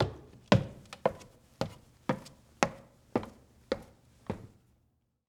Footsteps